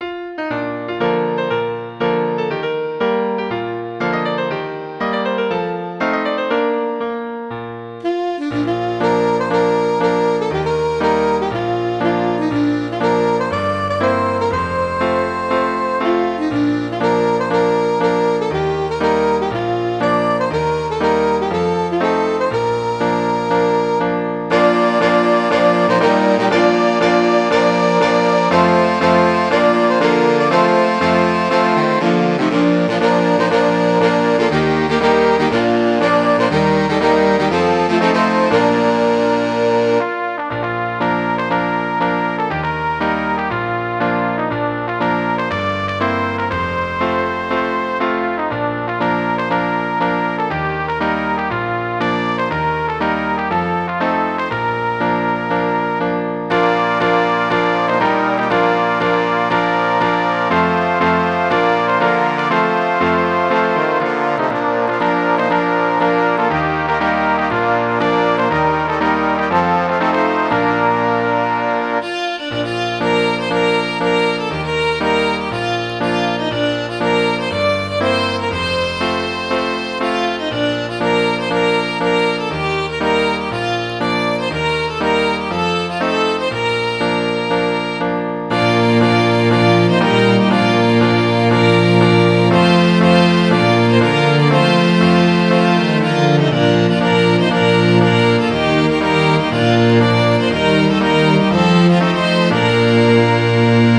Midi File, Lyrics and Information to Tramp!